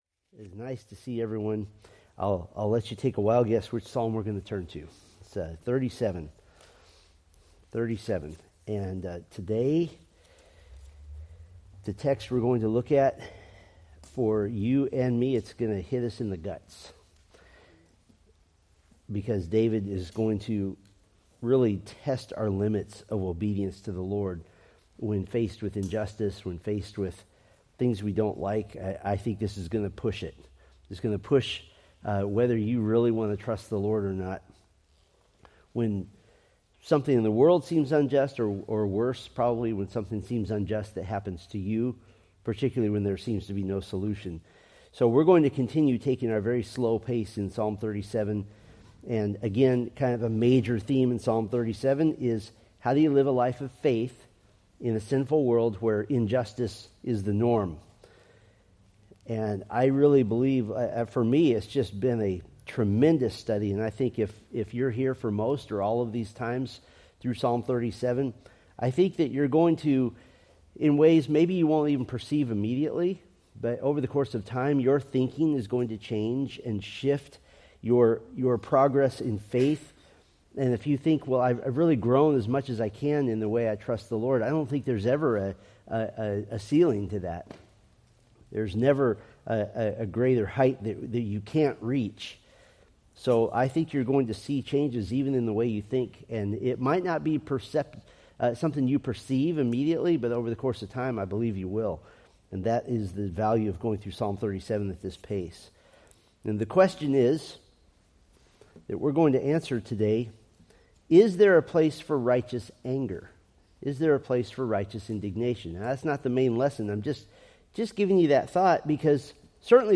Date: Feb 23, 2025 Series: Psalms Grouping: Sunday School (Adult) More: Download MP3